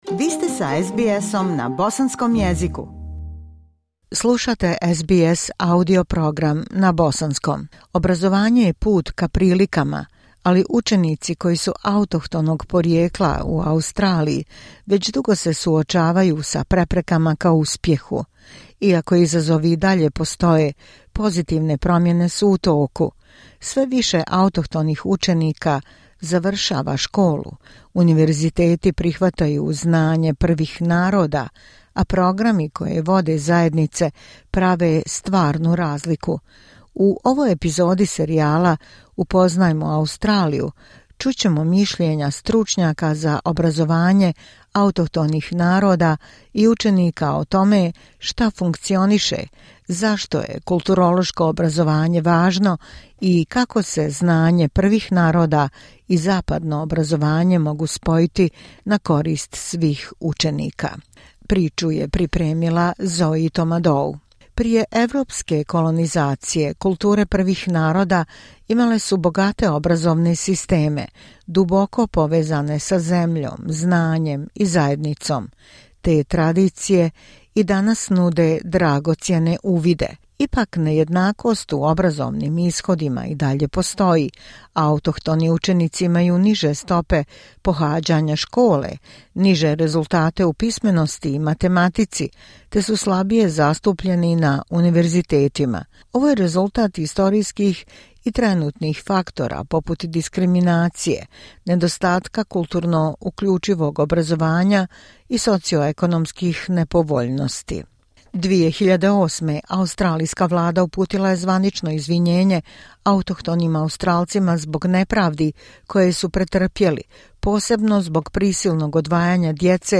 U ovoj epizodi serijala "Upoznajmo Australiju" (Australia Explained), slušaćemo autohtone stručnjake za obrazovanje i same učenike, koji govore o tome šta daje rezultate, zašto je kulturno obrazovanje od suštinske važnosti i kako se autohtono i zapadno znanje mogu uspješno spojiti — na dobrobit svih učenika.